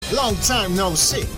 PLAY Rehehehe
Play, download and share HEH original sound button!!!!